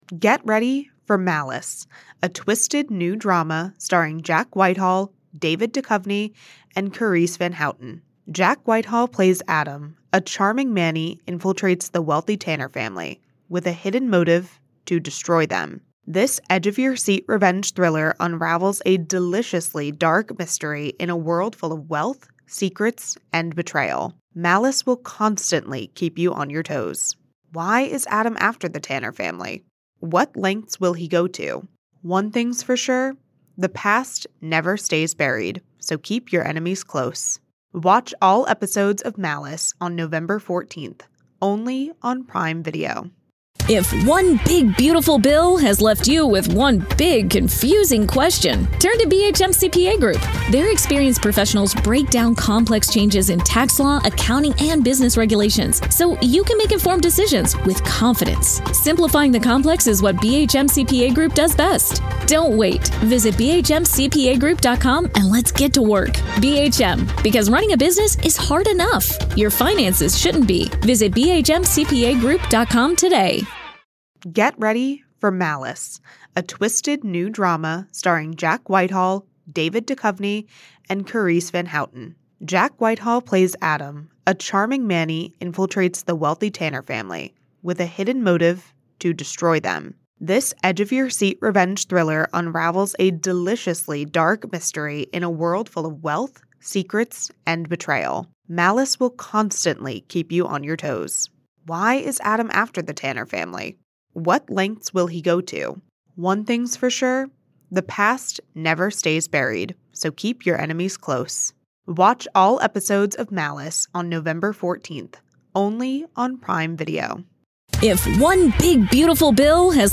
From high-profile criminal trials to in-depth examinations of ongoing investigations, this podcast takes listeners on a fascinating journey through the world of true crime and current events. Each episode navigates through multiple stories, illuminating their details with factual reporting, expert commentary, and engaging conversation.